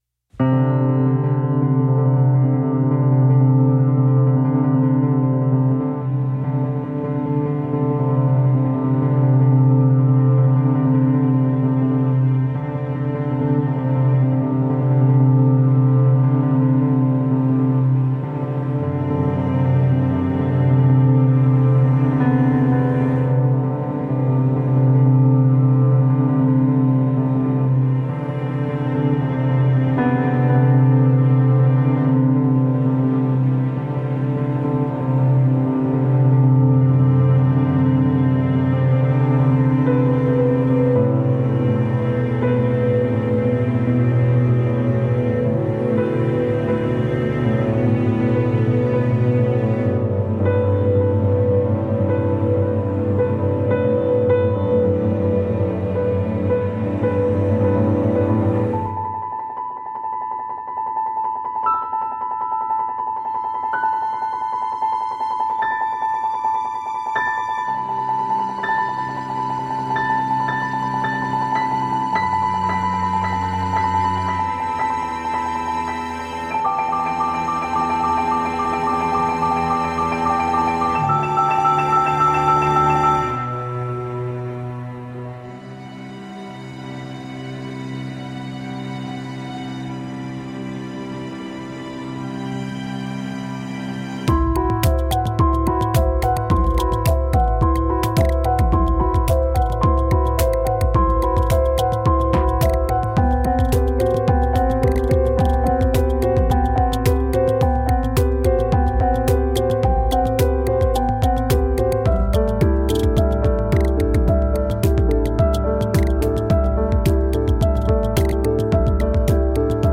modular, synthesizer
piano, synthesizer